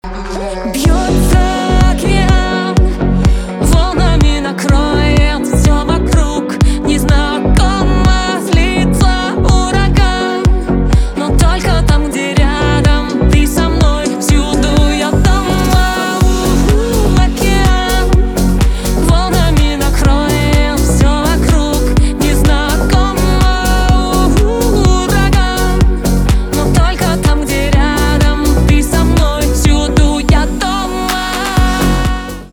поп
битовые , басы
чувственные